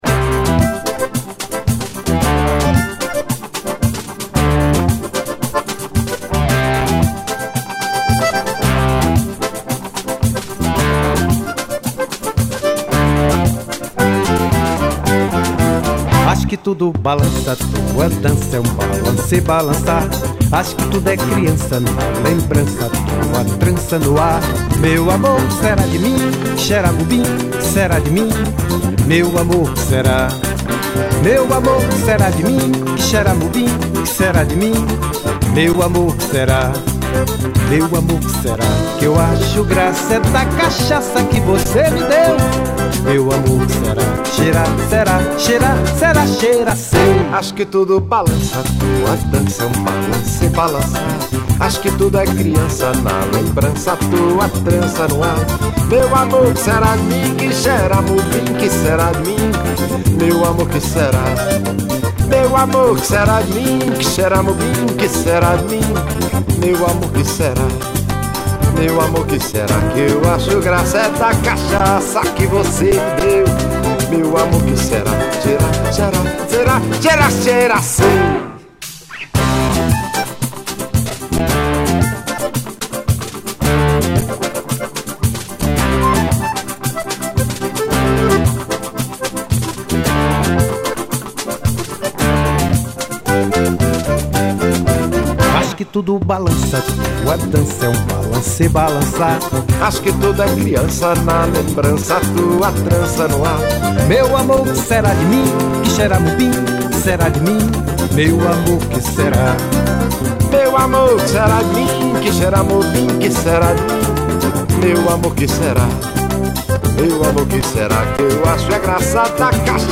1089   02:44:00   Faixa:     Forró
Acoordeon